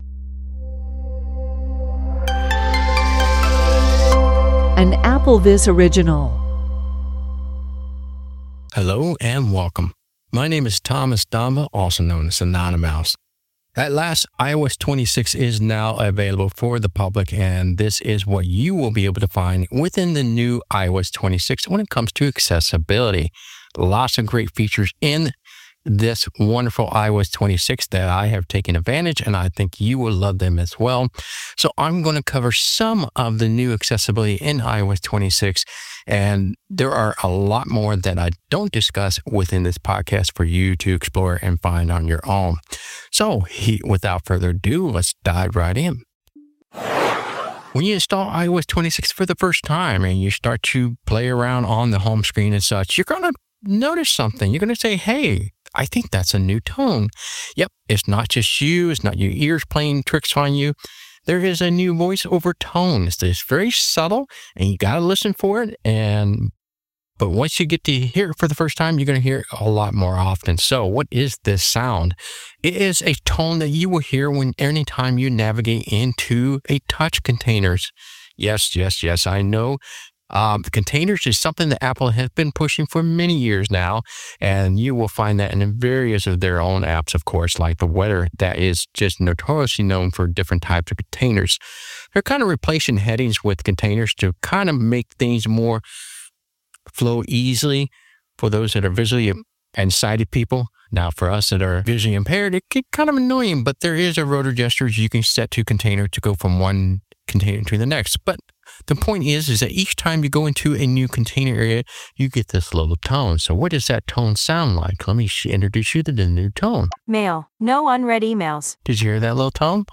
Whether you’re a long-time VoiceOver user or just curious about Apple’s accessibility innovations, this episode guides you through the highlights with real examples, demonstrations, and practical insights.